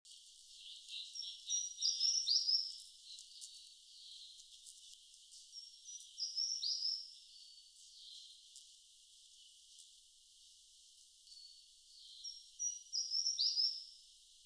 28-3自忠特富野2012mar26麟胸鷦鷯1.mp3
臺灣鷦眉 Pnoepyga formosana
錄音地點 南投縣 信義鄉 自忠
錄音環境 森林
行為描述 鳴唱